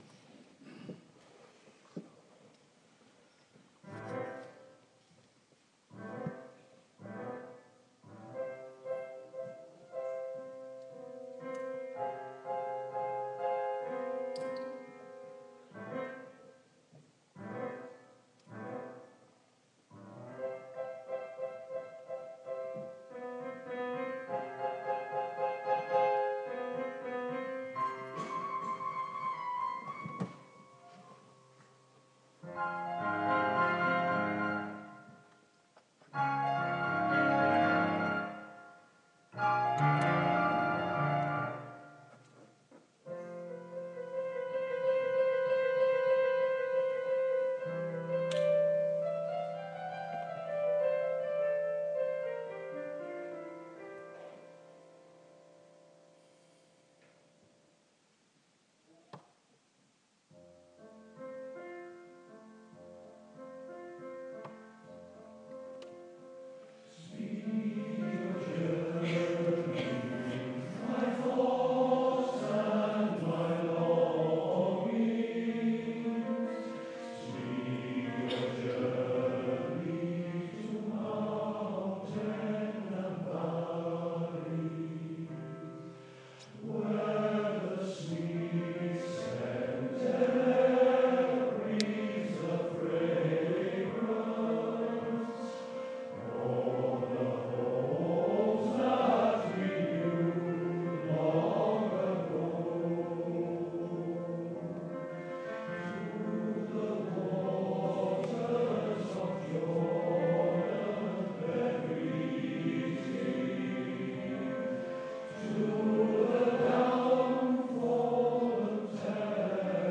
Driffield Male Voice Choir performing in Seamer